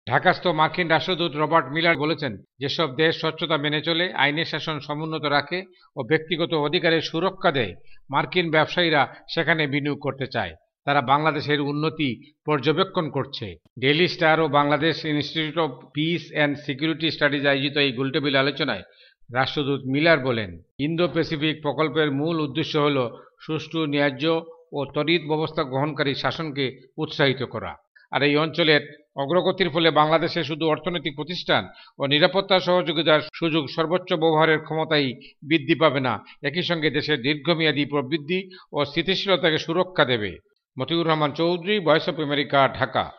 ঢাকাস্থ মার্কিন রাষ্ট্রদূত রবার্ট মিলার ইন্দো-প্যাসিফিক স্ট্যাটেজি ফর দ্য রিজিউন শীর্ষক এক গোলটেবিল আলোচনায় স্পষ্ট করে বলেছেন, যেসব দেশ স্বচ্ছতা মেনে চলে, আইনের শাসন সমুন্নত রাখে ও ব্যক্তিগত অধিকারের সুরক্ষা দেয় মার্কিন ব্যবসায়ীরা সেখানে বিনিয়োগ করতে চায়।